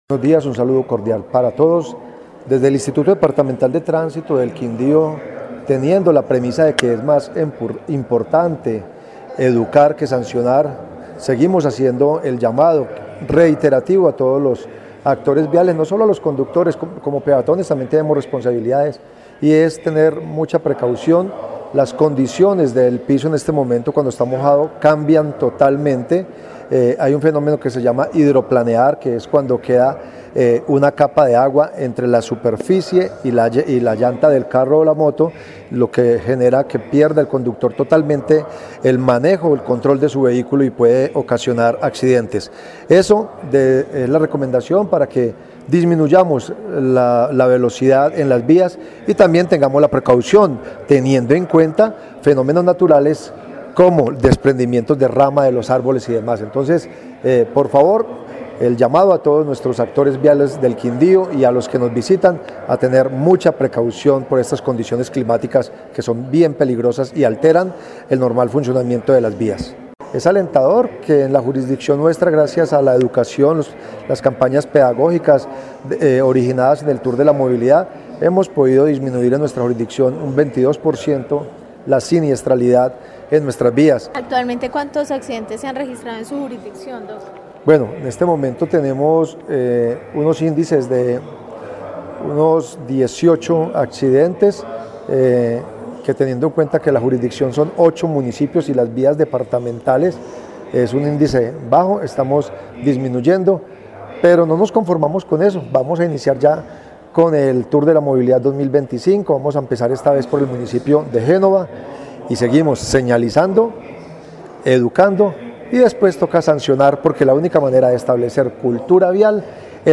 Director de IDTQ